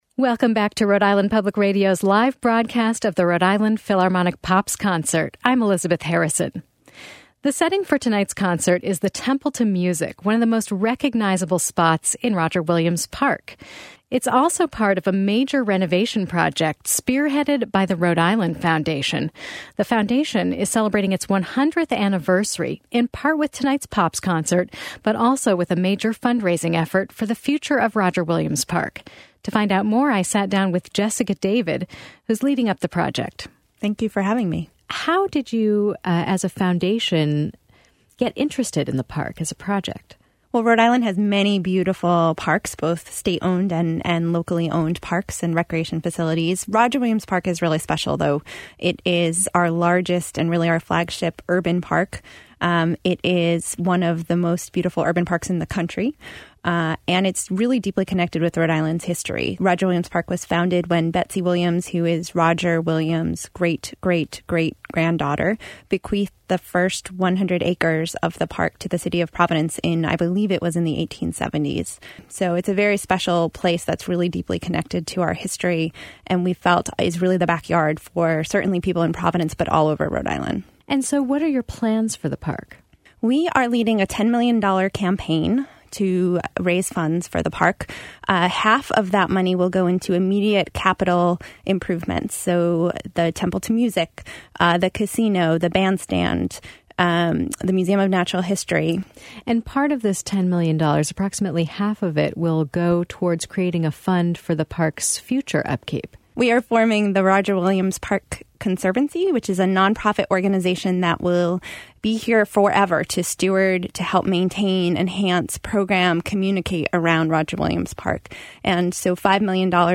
Rhode Island Public Radio and Latino Public Radio offered live broadcasts of the concert.